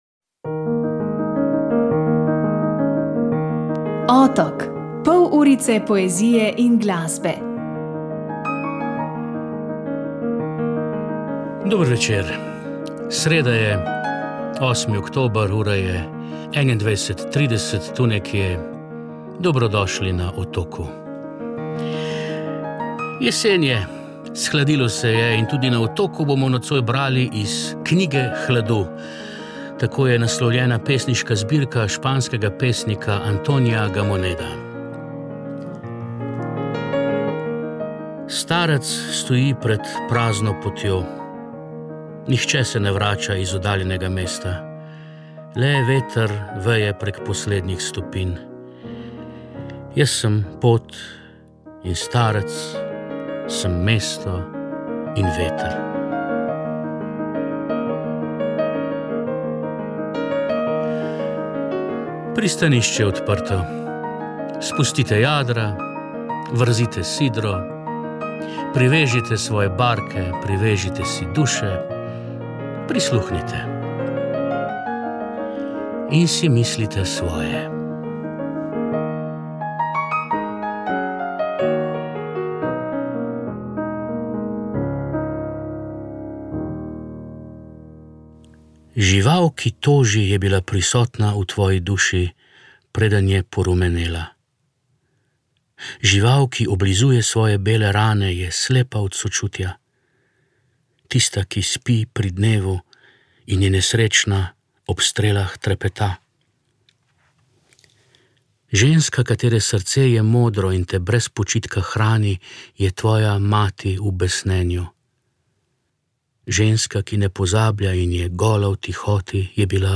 Državni zbor je sprejel spremembe krovnega zakona za Slovence v zamejstvu in po svetu. Kako jih je predstavila državna sekretarka Vesna Humar, ste lahko slišali na začetku.
V Gorici in Novi Gorici so se zbrali predstavniki slovanski manjšin iz vse Evrope [več ob 18:48]. Ob koncu oddaje pa še, kaj je na Dragi 2025 na omizju Zaton zahod povedala Bernarda Fink Inzko [pri 27:14].